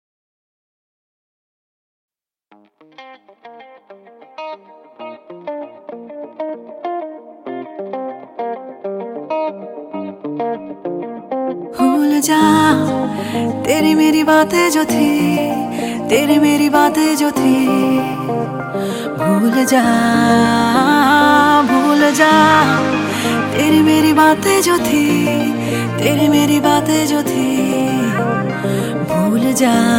Ringtone